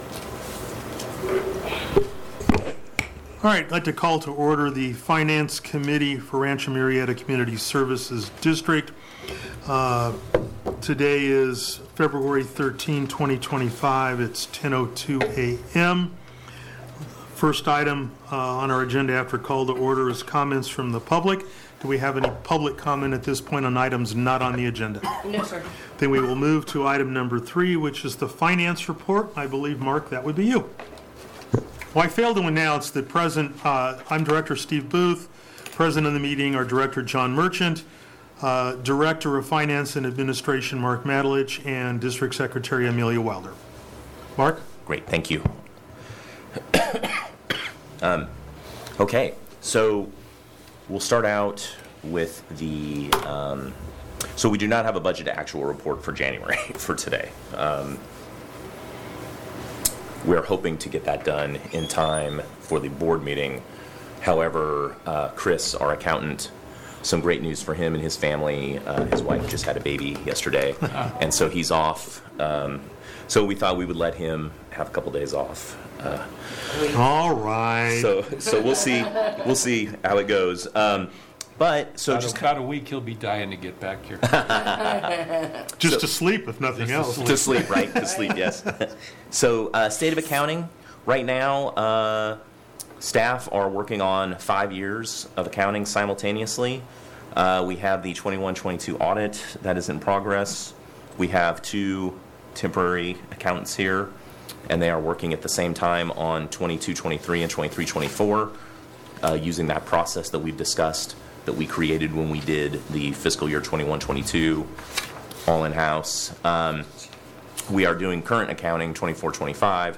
Finance Committee Meeting